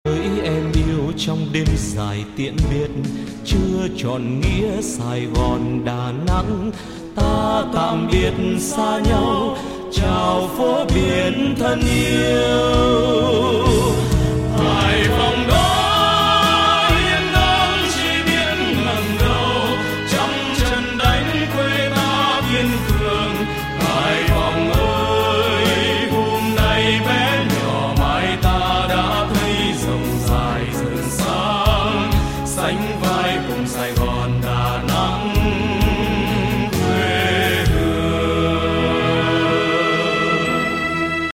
Nhạc Chuông Nhạc Vàng - Nhạc Đỏ